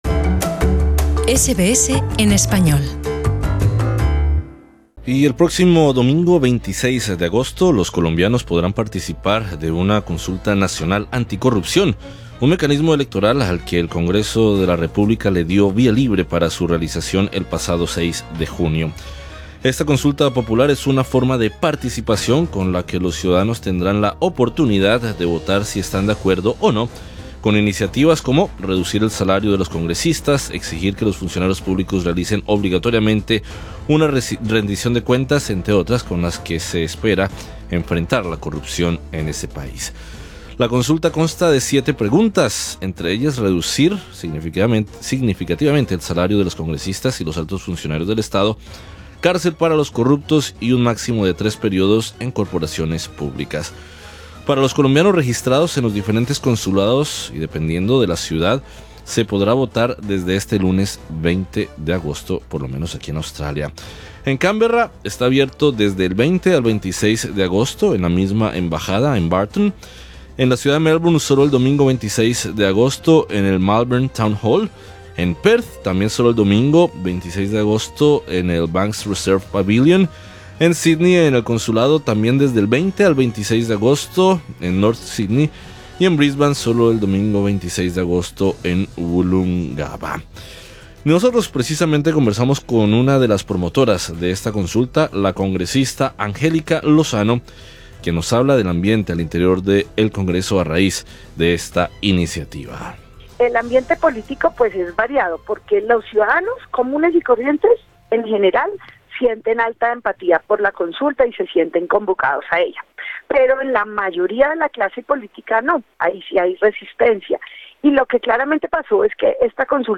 Entrevista con la congresista Angélica Lozano, una de las promotoras de la consulta.